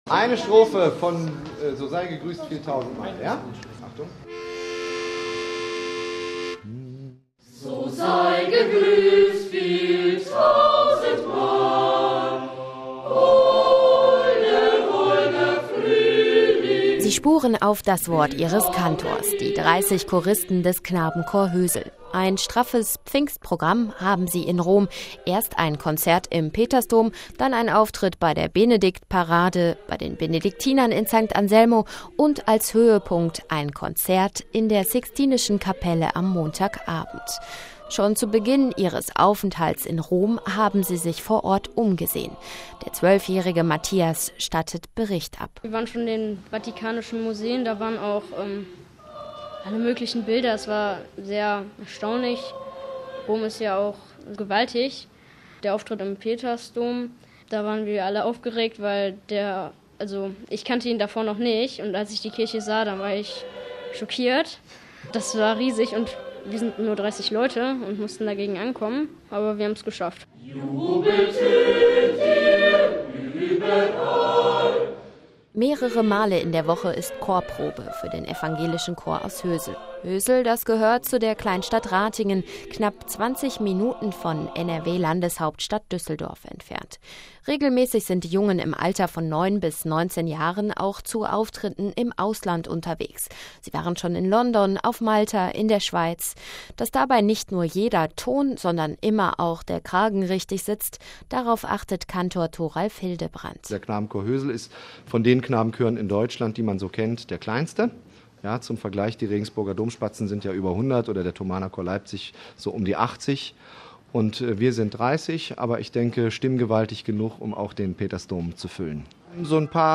Sixtina: Evangelischer Knabenchor aus Deutschland singt